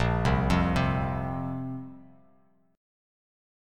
Bbsus2 chord